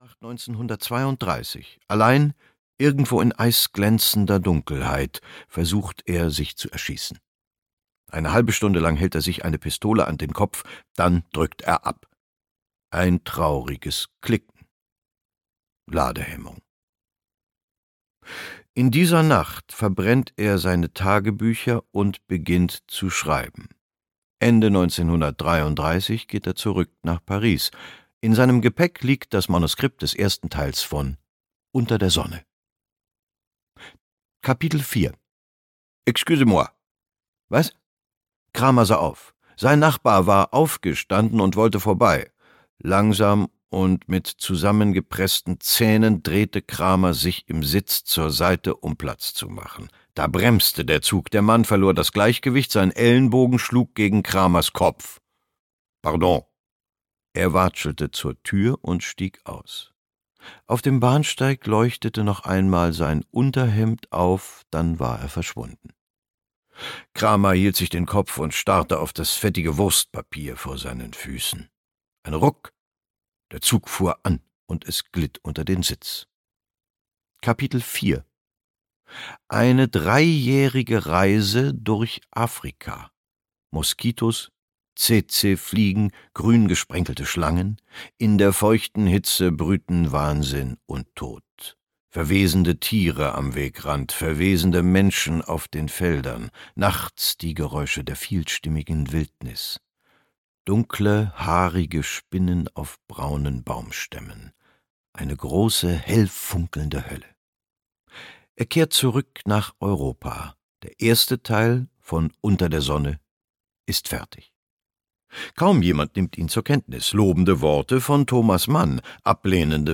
Unter der Sonne - Daniel Kehlmann - Hörbuch